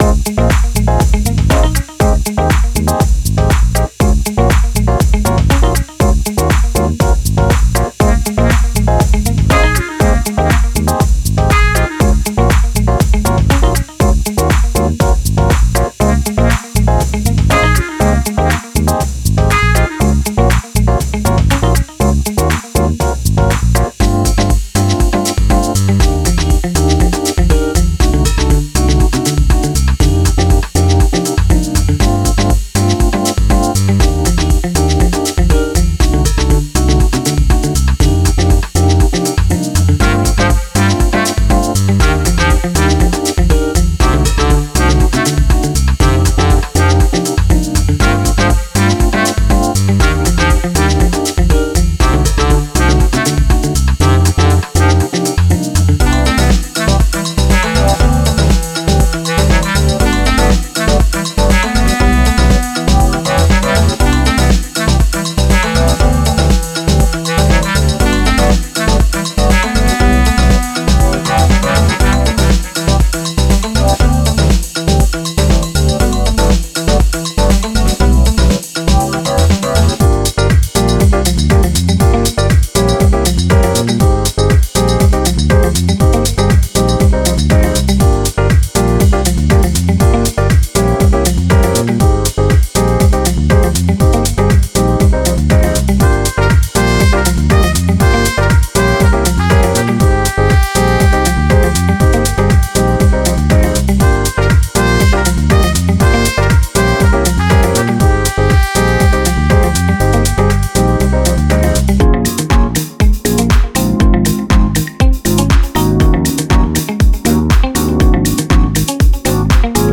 Wav Loops